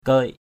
/kəɪ:ʔ/ (tr.) chứ = particule exclamative. exclamatory particle. ngap o kec!